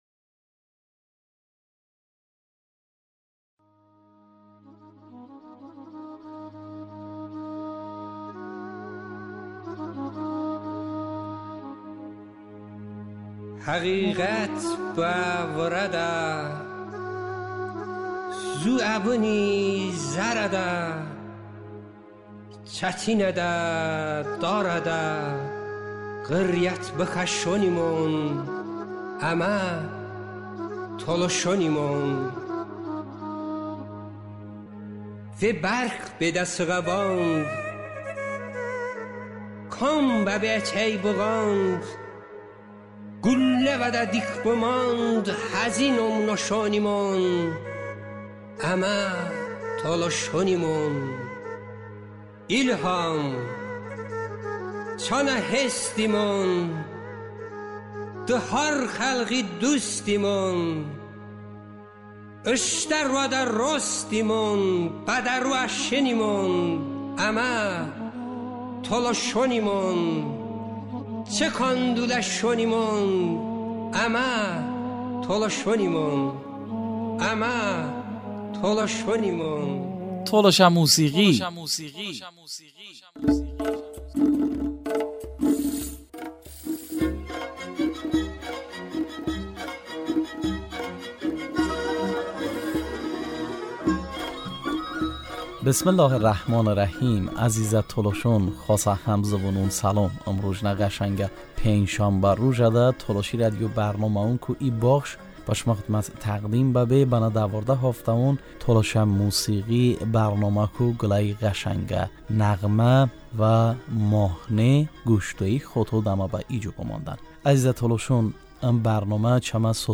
De İroni Masal-Şanderməni ləhcə Tolışə nəğmə...